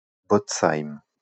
Bootzheim (French pronunciation: [botsaim]